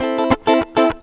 GTR 99 AM.wav